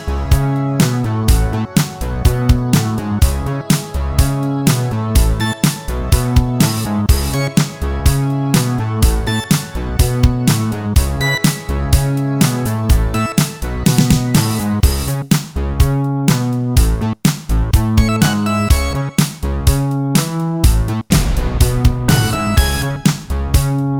Single Version Pop (1980s) 3:46 Buy £1.50